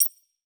Epic Holographic User Interface Click 1.wav